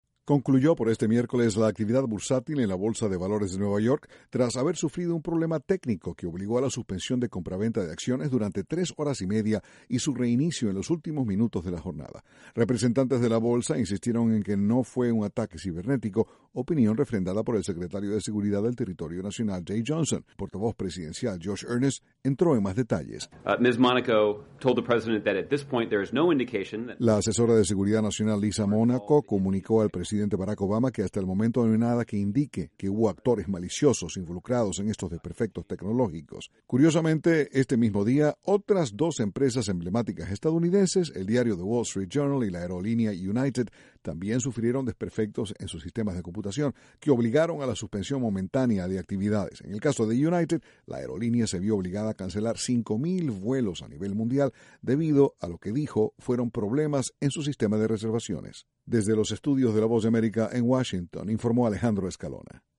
Desde la Voz de America, Washington, informa